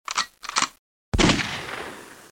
子弹上膛射击音效免费下载素材
SFX音效